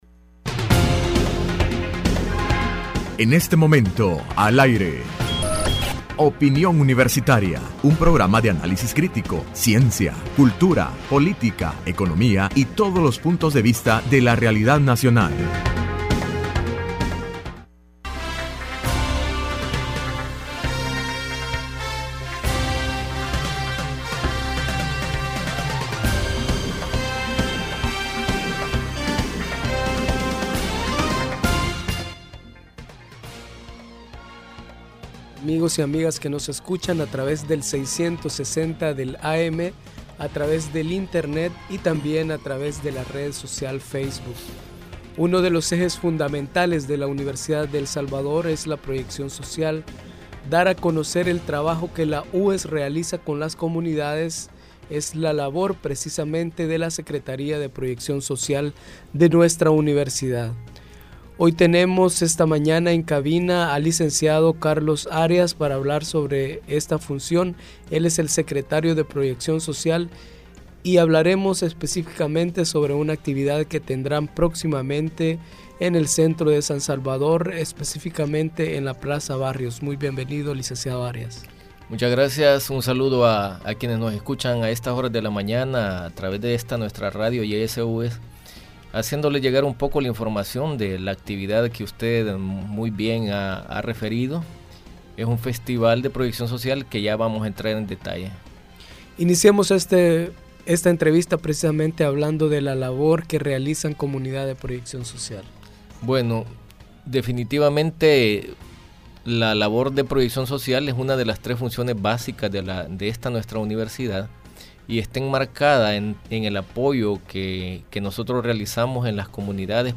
Entrevista Opinión Universitaria (28 de septiembre 2016): Proyección social de la UES